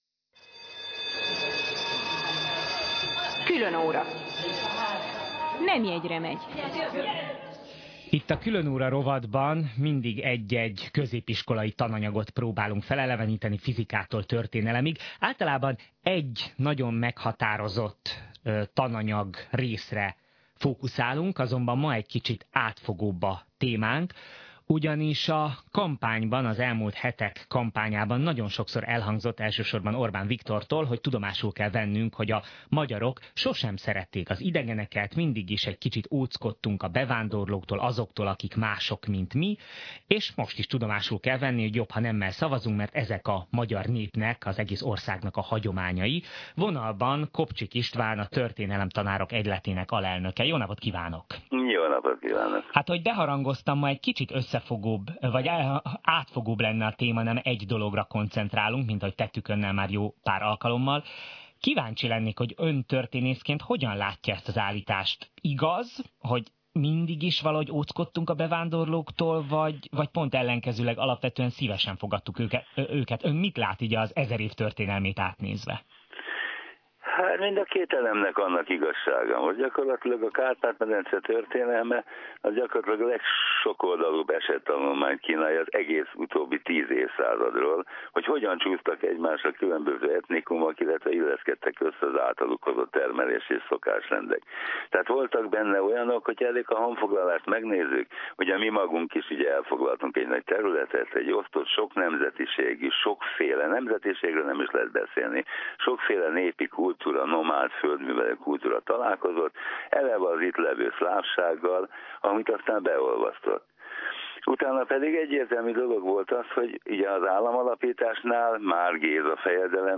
Az interjú itt meghallgatható és letölthető